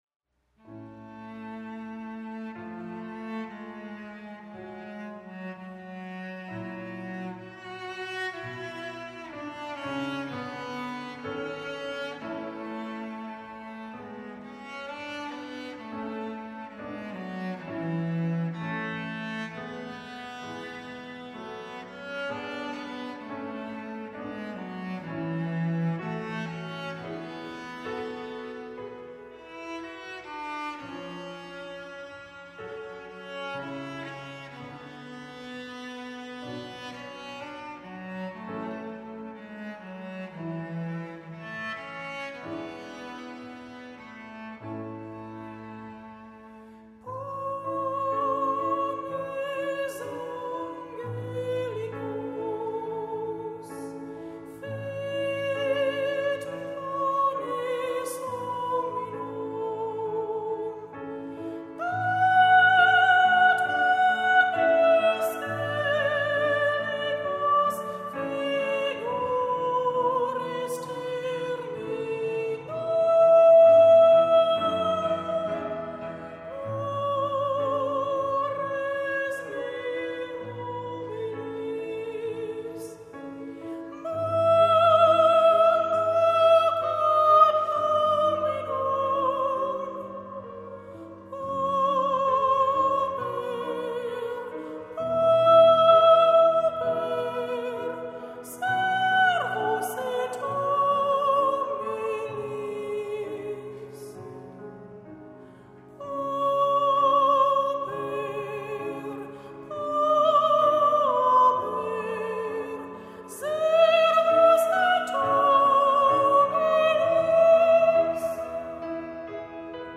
Musik-Workshop & CD-Produktion 2011
Traditionelle Geistliche Chormusik
für 5stimmigen Chor, Bass-Solo, Streicher und B.C.